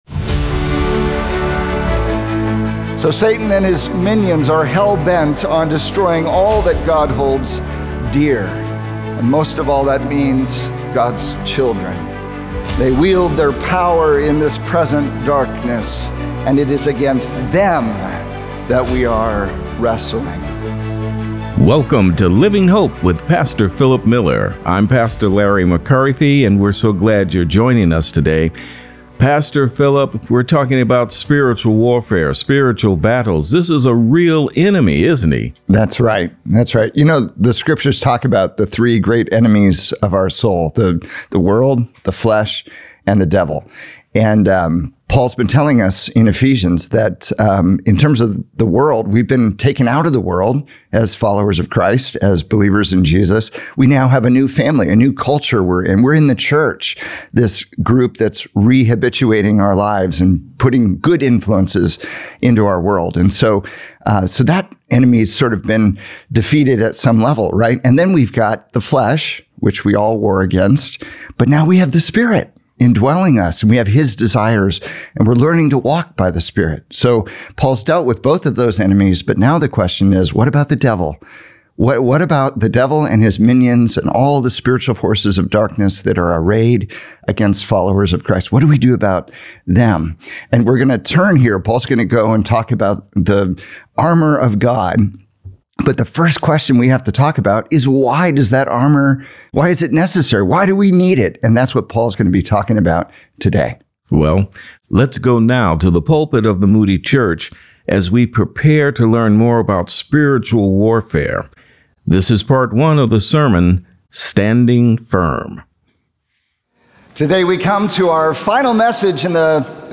Evil is not just abstract but intensely personal. Ephesians 6 reveals the dramatic shift from being a casualty to standing firm. In this message